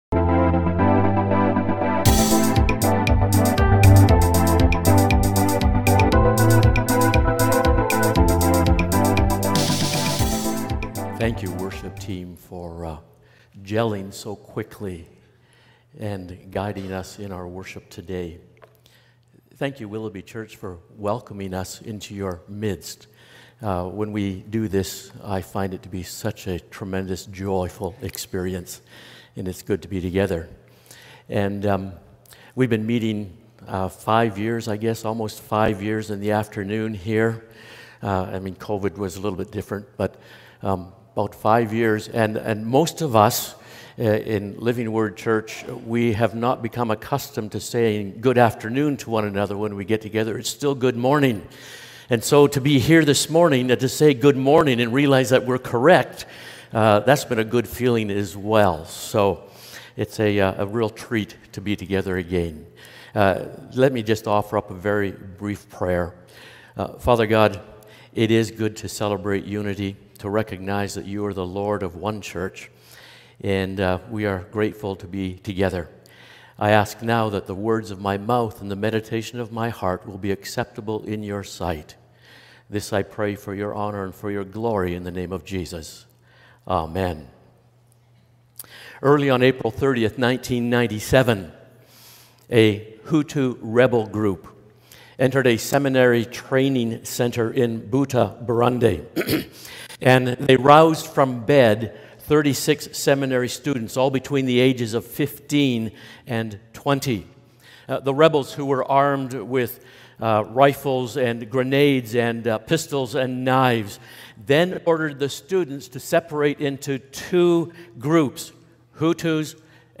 Willoughby Church Sermons | Willoughby Christian Reformed Church
For our worship service this Sunday we will be having a combined service with The Living Word Church.